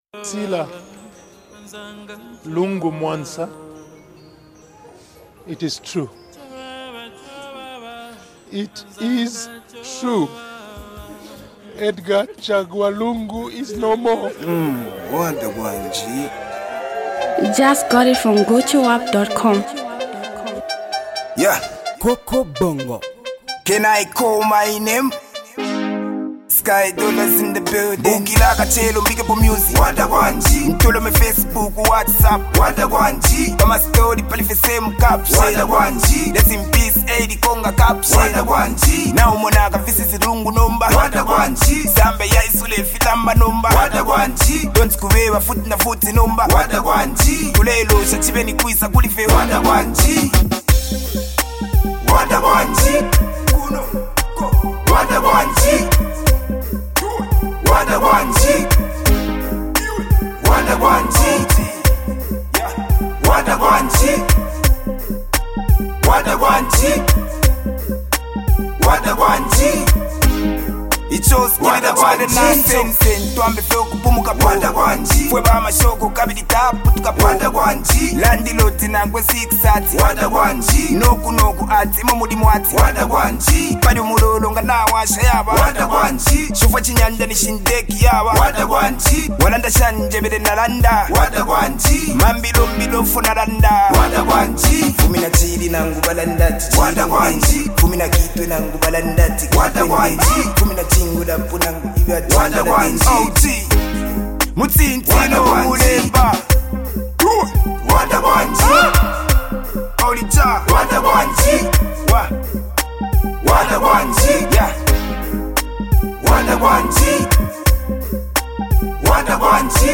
Zambian Music, Tribute Song.